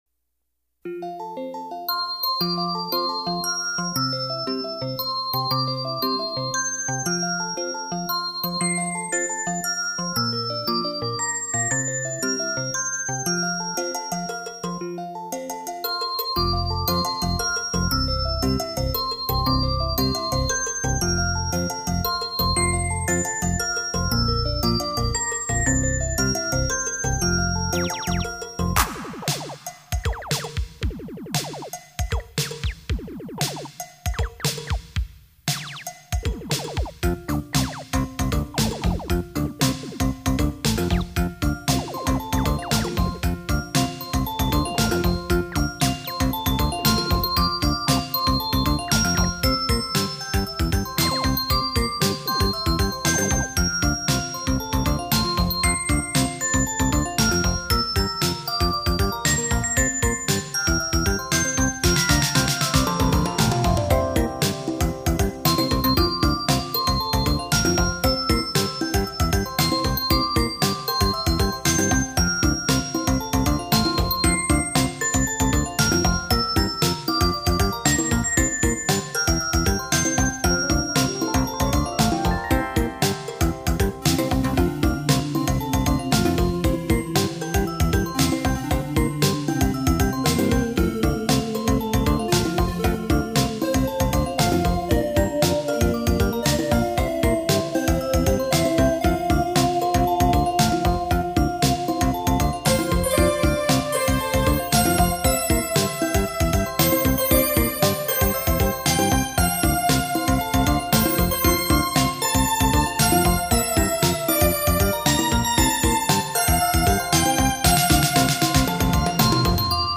幽秘的旋律轻轻飘落床前，如梦如幻的音符掠过心湖，宁静夜晚音乐是梦的天堂。